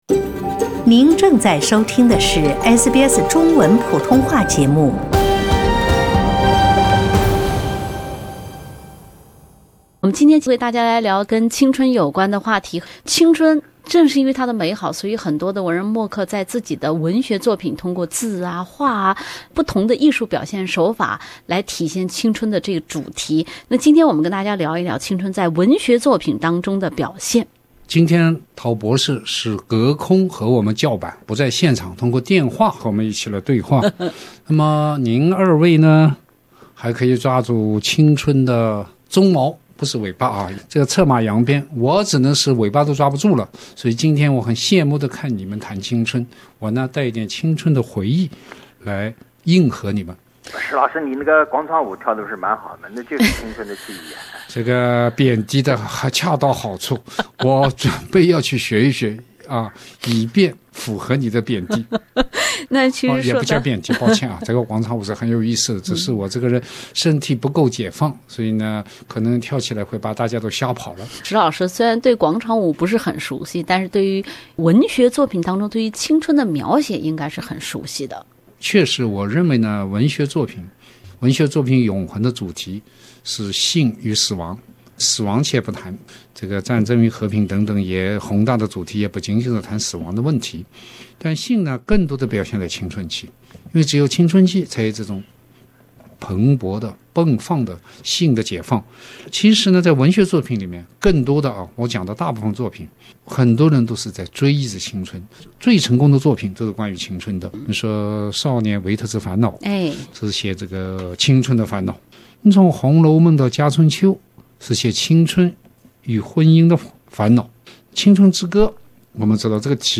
又是一年过去了，天增岁月，人增寿命，我们每一个人又在向着与“青春”背道而驰的道路上迈出了坚实的一步。（点击封面图片，收听完整对话）